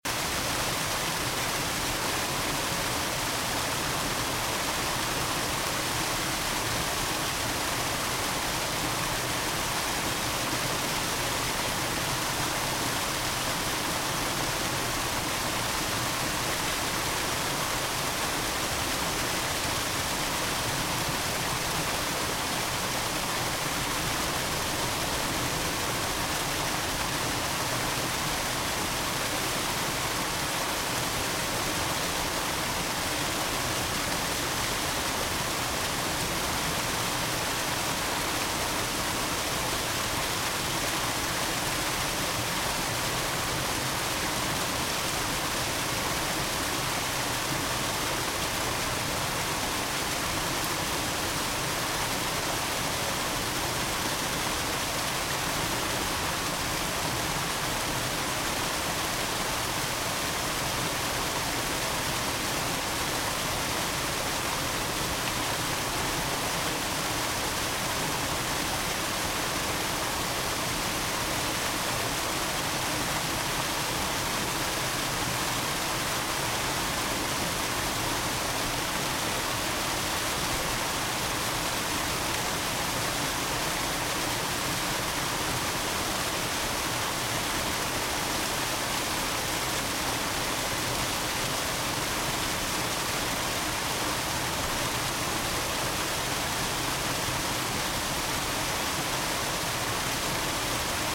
A_Waterfall.ogg